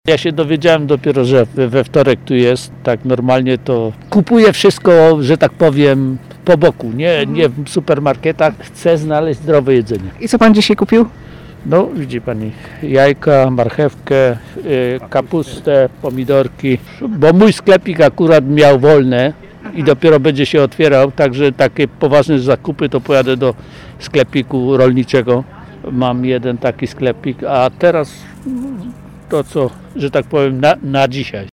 Posłuchaj Choć w mniejszości, kupujący też byli.